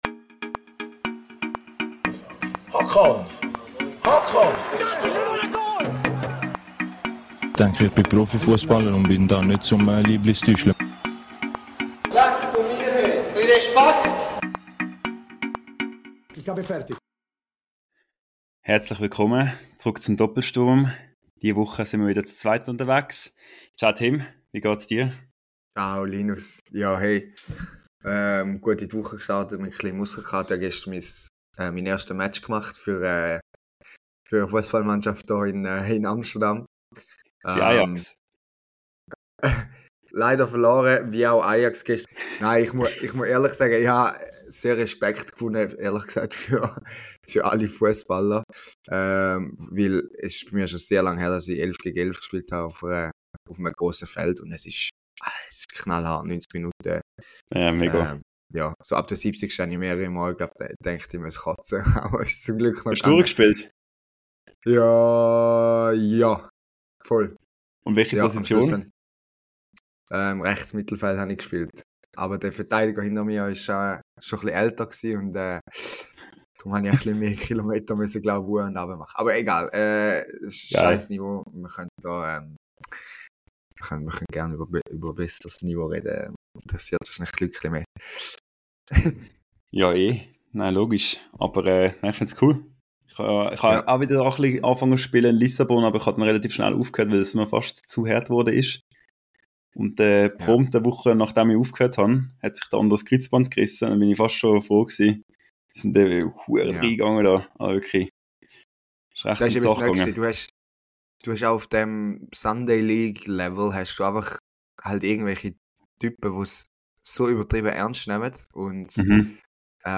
Seit nunmehr über einem Jahr nehmen wir, zwei Sofaexperten und Schweizer Fussballfans im holländischen und portugiesischen Exil, diesen Fussball-Podcast mit viel Freude und Leidenschaft auf.
Heute wieder einmal mit einer ganz simplen Folge ohne Gast, nach einem doch sehr ereignisreichen Schweizer Fussballwochenende.